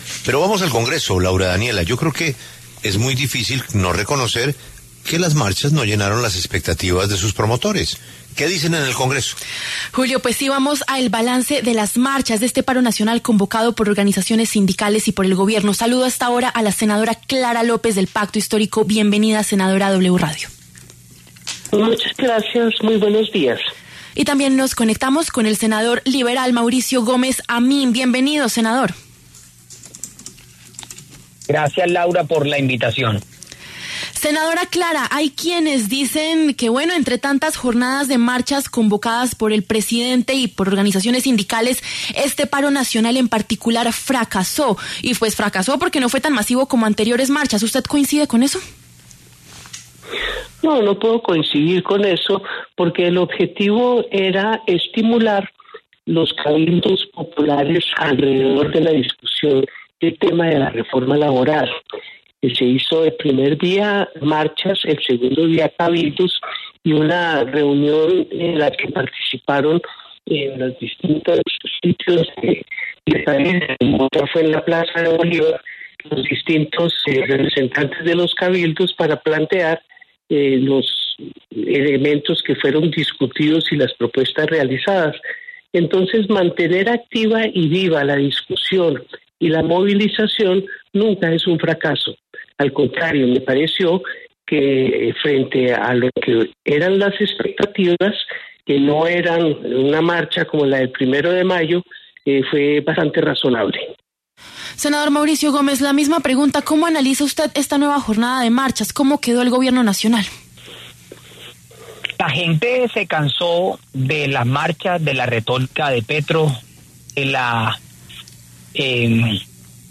Los congresistas Clara López, del Pacto Histórico, y Mauricio Gómez Amín, del Partido Liberal, hablaron en los micrófonos de La W.